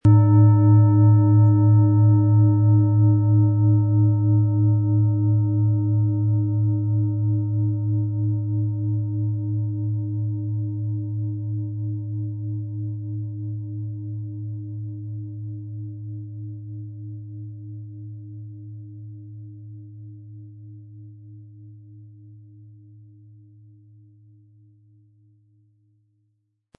Es ist eine nach uralter Tradition von Hand getriebene Planetenton-Klangschale Eros.
Durch die traditionsreiche Herstellung hat die Schale stattdessen diesen einmaligen Ton und das besondere, bewegende Schwingen der traditionellen Handarbeit.
Den passenden Schlegel erhalten Sie kostenfrei mitgeliefert, der Schlägel lässt die Schale voll und wohltuend erklingen.
MaterialBronze